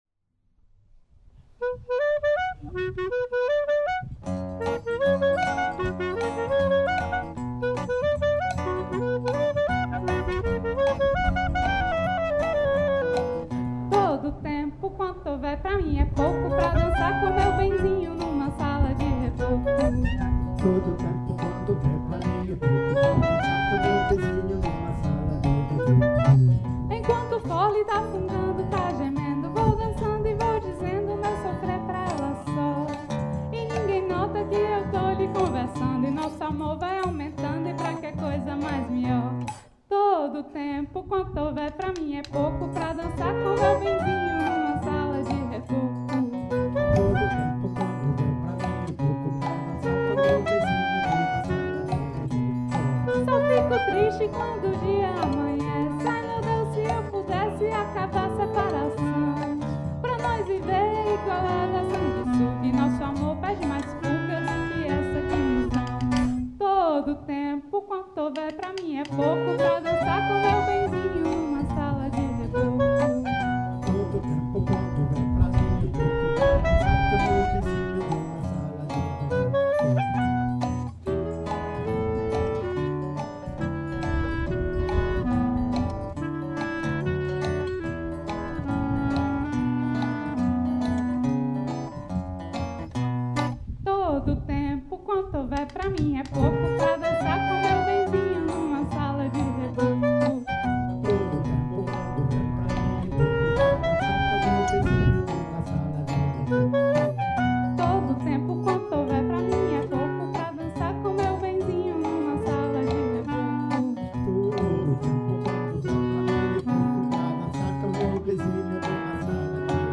Joué pour la fête de la Musique 2024